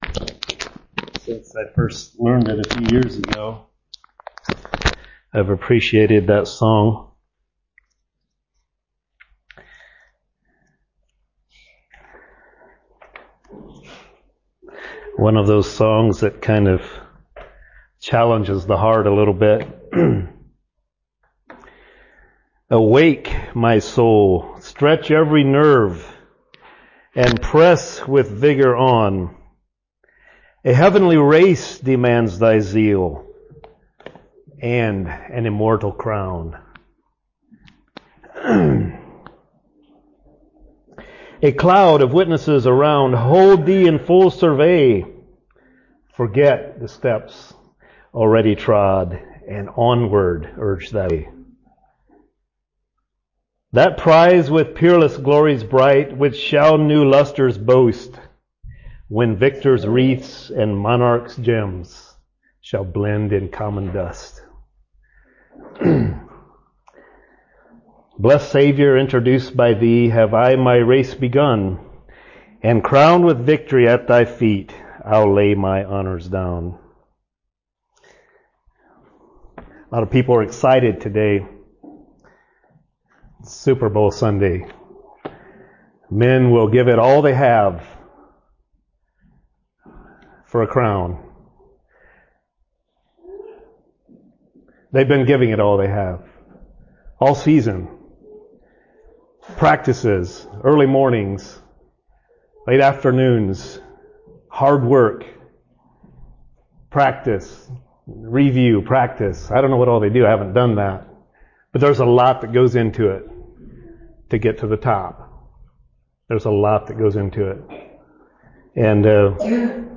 Listen to and download sermons preached in 2025 from Shelbyville Christian Fellowship.